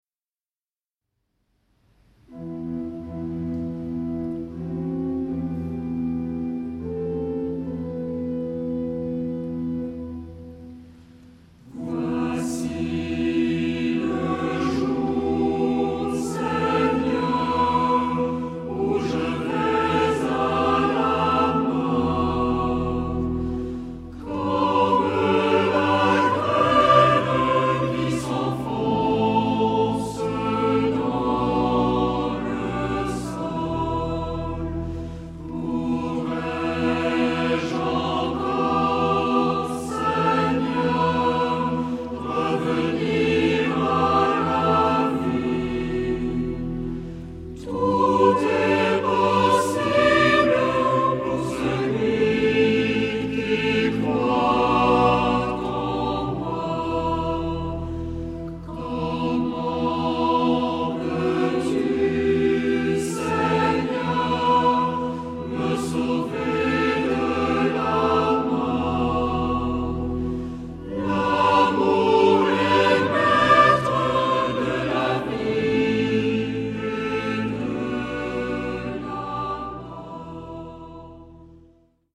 Genre-Style-Forme : Prière
Caractère de la pièce : majestueux ; andante ; calme
Type de choeur : SAH  (3 voix mixtes OU unisson )
Tonalité : mi mineur